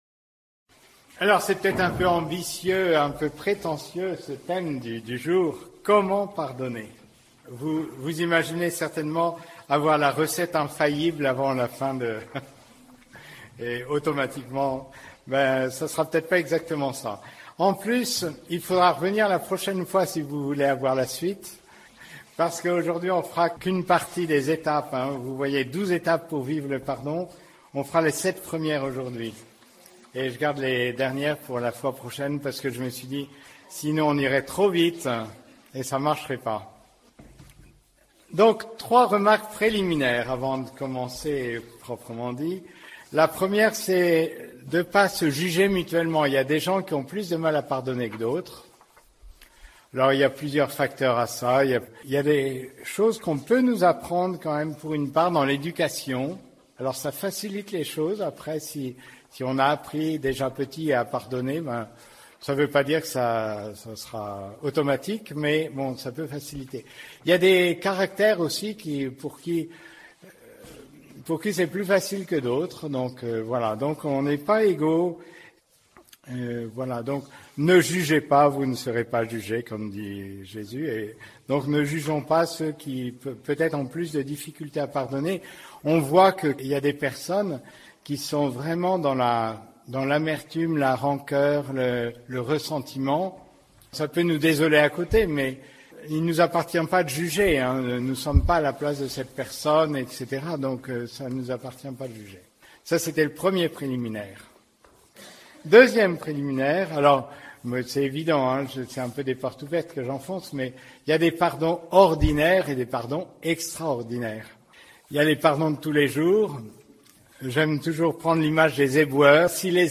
haltes_spirituelles_ourscamp_6_jubile_comment_pardonner_mars_2025.mp3